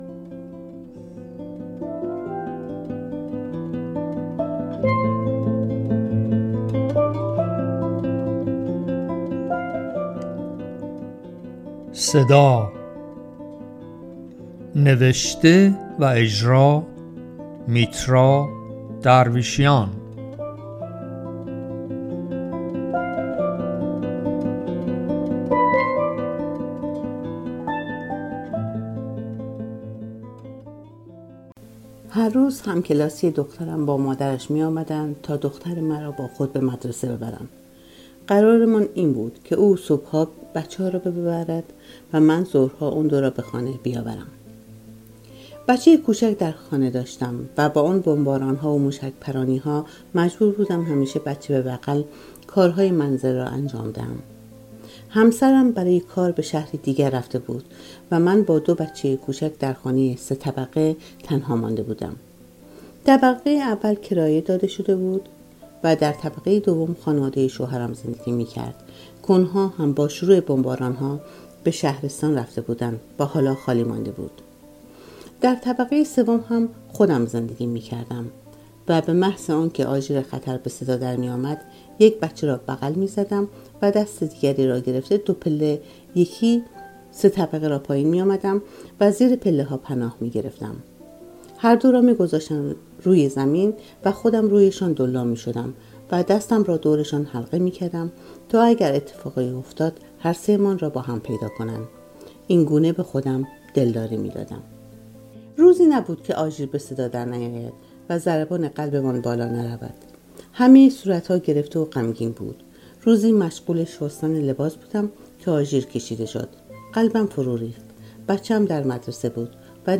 داستان خوانى برنامه اى از رادیو فرهنگ